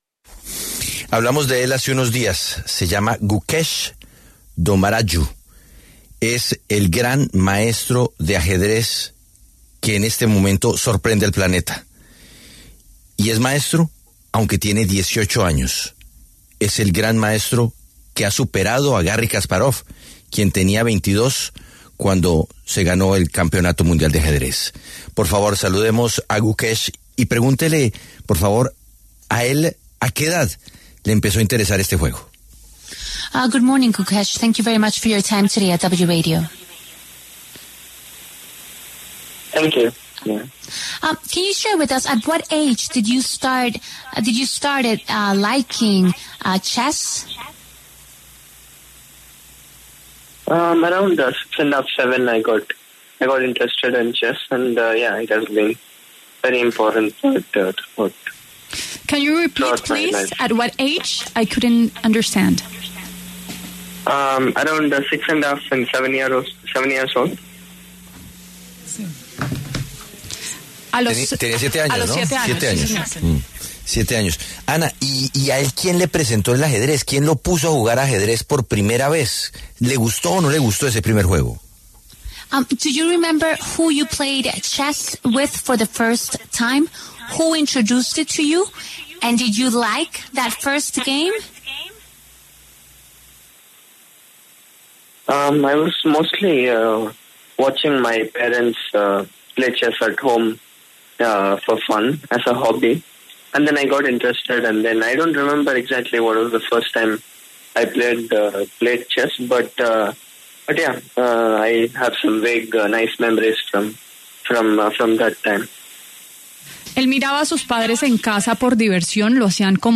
Gukesh Dommaraju, campeón del mundo de ajedrez más joven de la historia, conversó con La W a propósito de esta hazaña.